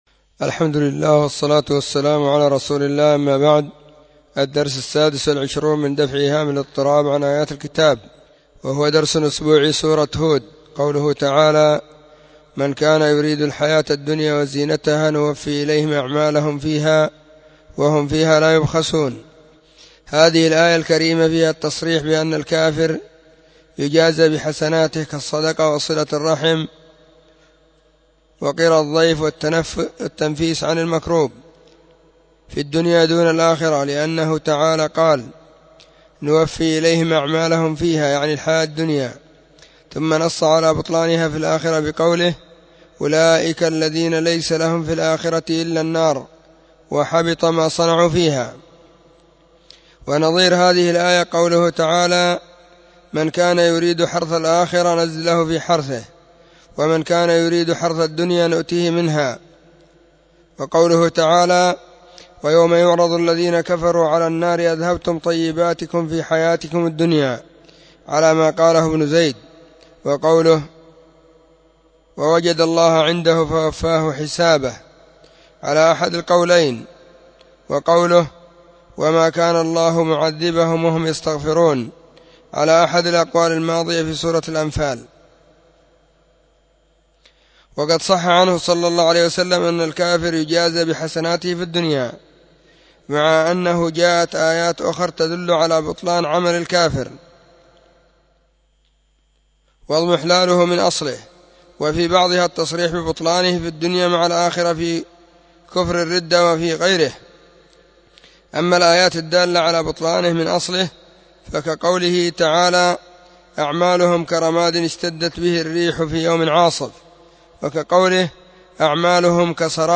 📢 مسجد الصحابة – بالغيضة – المهرة، اليمن حرسها الله.
كتاب-دفع-إيهام-الاضطراب-الدرس-26.mp3